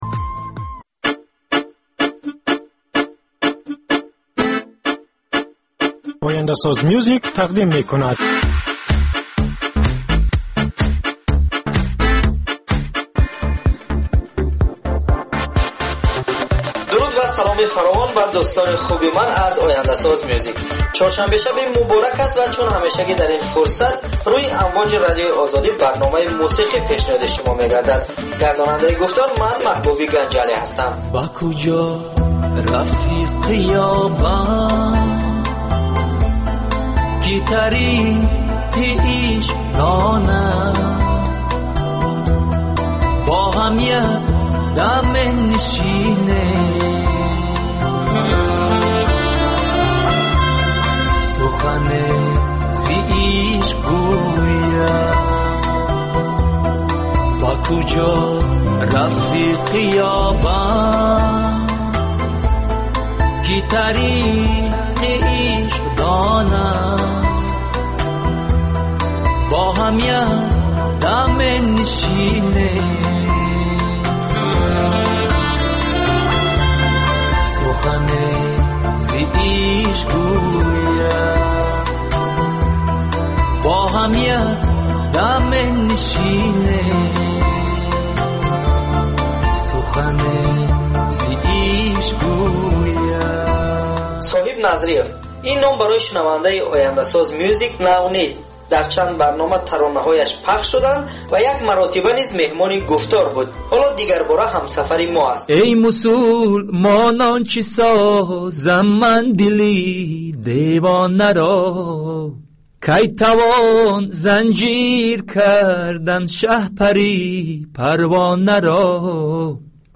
Ахбори фарҳангӣ, гуфтугӯ бо овозхонони саршинос, баррасии консерт ва маҳфилҳои ҳунарӣ, солгарди ходимони ҳунар ва баррасии саҳми онҳо.